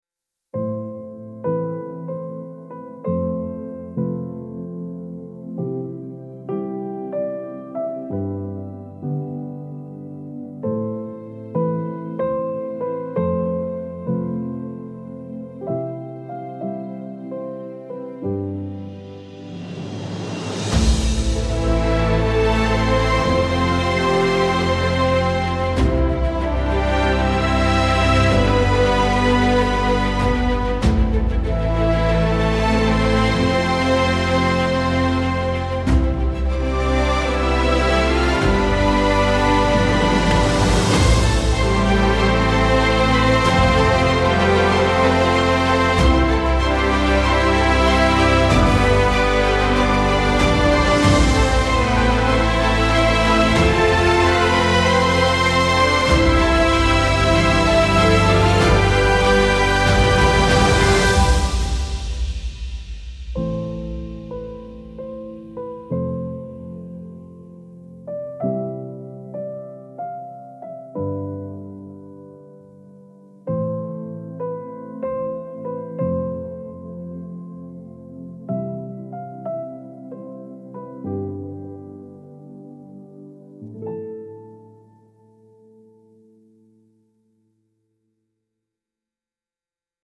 Hans Zimmer inspired epic love theme with brass, timpani and sweeping strings